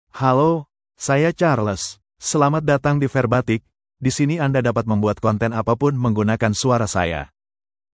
CharlesMale Indonesian AI voice
Charles is a male AI voice for Indonesian (Indonesia).
Voice sample
Listen to Charles's male Indonesian voice.
Male
Charles delivers clear pronunciation with authentic Indonesia Indonesian intonation, making your content sound professionally produced.